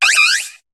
Cri de Vipélierre dans Pokémon HOME.